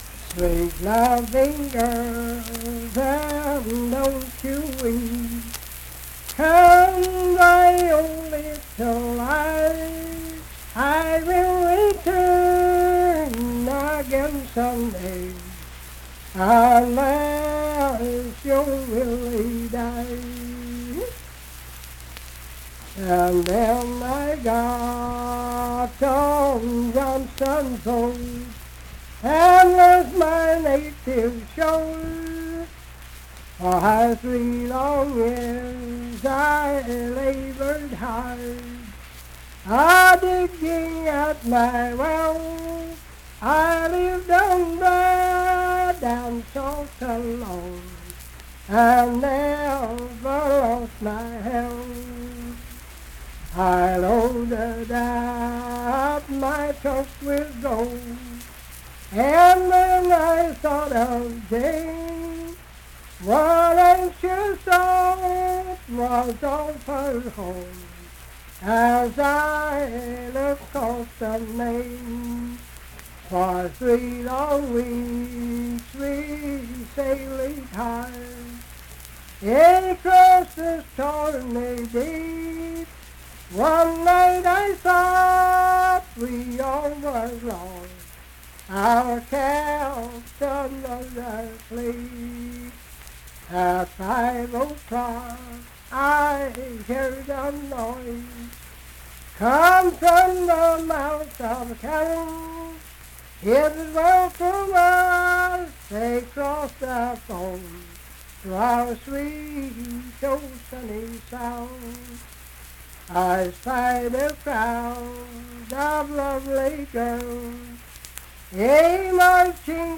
Unaccompanied vocal music
Verse-refrain 10(4). Performed in Ivydale, Clay County, WV.
Voice (sung)